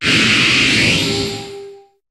Cri de Mouscoto dans Pokémon HOME.